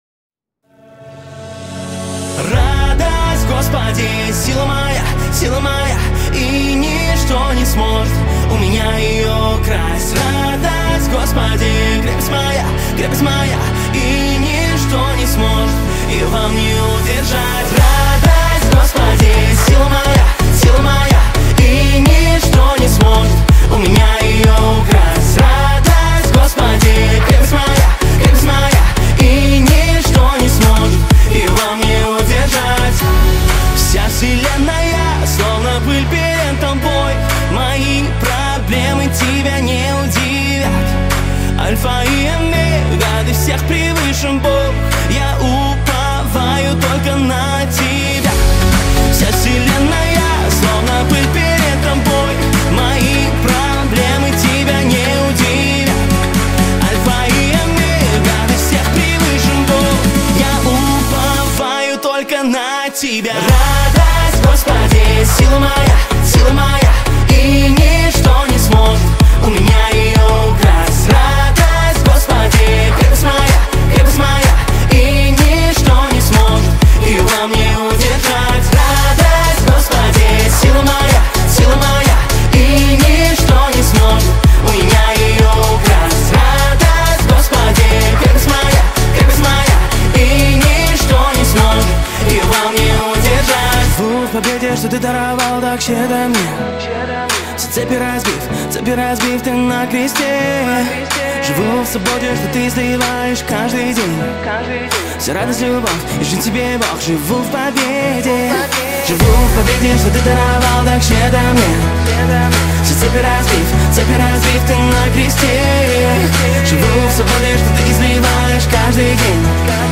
6411 просмотров 8123 прослушивания 1123 скачивания BPM: 125